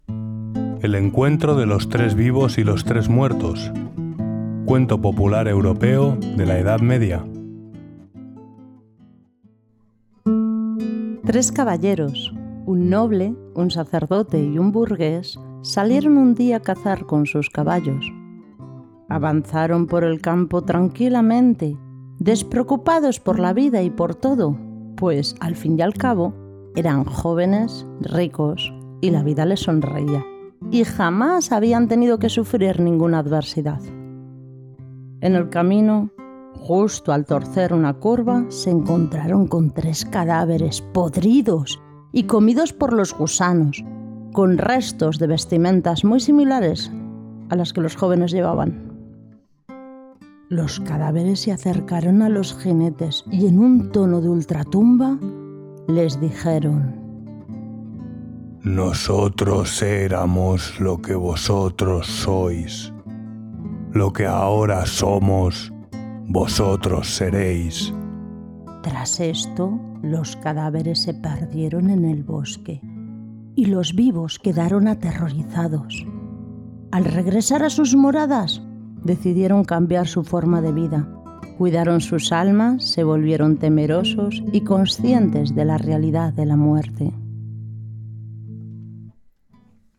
💀 Los Tres Vivos y los Tres Muertos ⚰ Cuento Medieval 🎶 Narrado en Español - Conmoraleja
(voz humana)